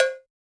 LINN COWBEL.wav